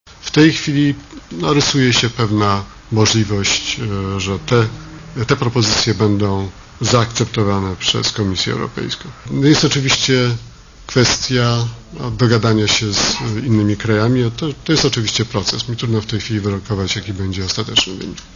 * Mowi minister Groński*